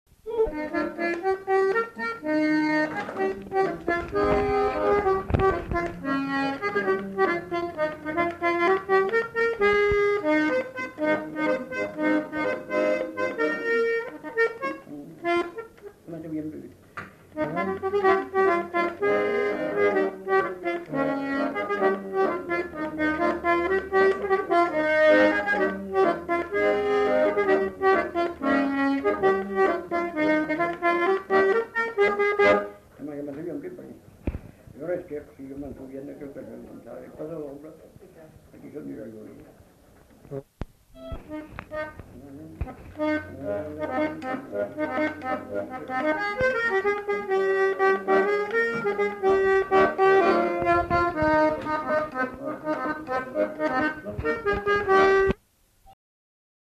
Aire culturelle : Marmandais gascon
Lieu : Mas-d'Agenais (Le)
Genre : morceau instrumental
Instrument de musique : accordéon diatonique
Danse : berline